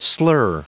Prononciation du mot slur en anglais (fichier audio)
Prononciation du mot : slur
slur.wav